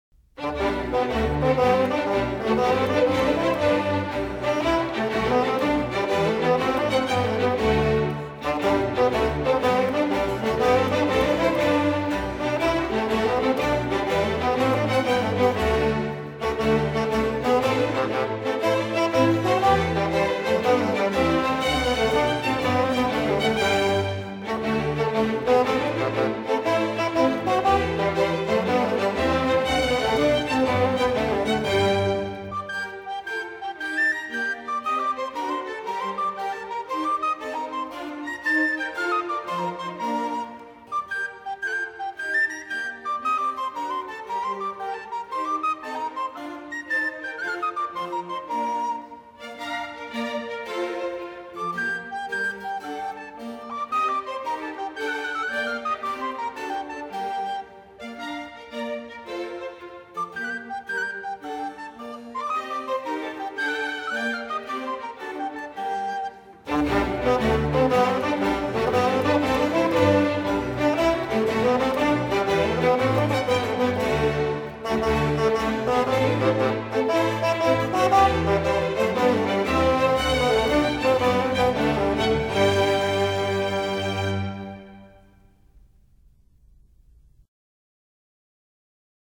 吉格舞曲
吉格舞曲是种流行于英格兰、爱尔兰乡村的活泼的舞曲，通常采用复二拍子或三拍子。